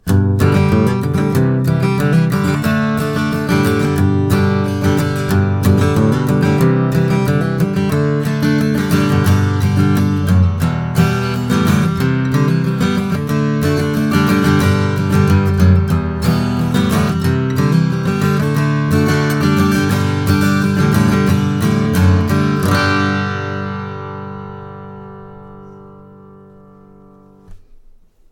Sloped Shoulder Dreadnought Spruce/Bosse Cedar with sound
The Bosse Cedar looks and feels like Mahogany, but sound wise it resembles more like Rosewood.
If you like deep and warm, this is the way to go.